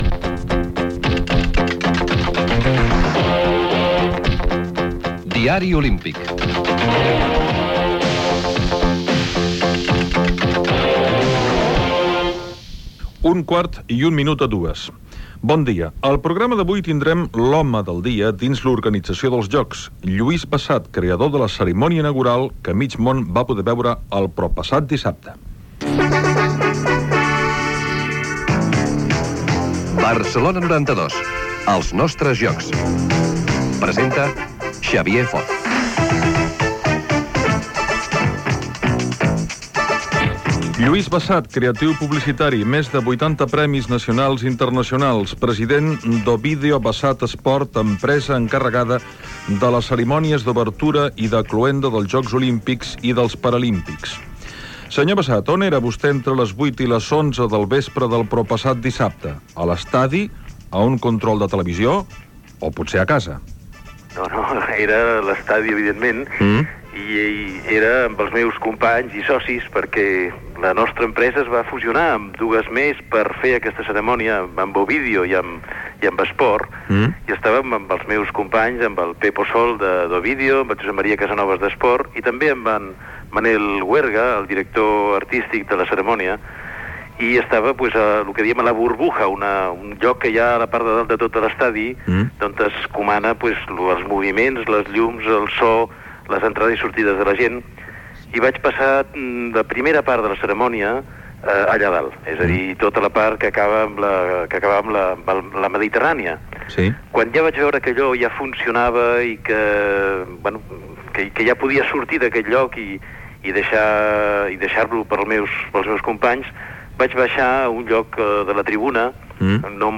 Careta del programa, presentació, sumari, indicatiu
Gènere radiofònic Informatiu